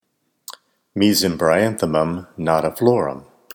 Mesembryanthemum nodiflorum --- Slender Leaved Iceplant --- Aizoaceae Pronunciation Cal Photos images Google images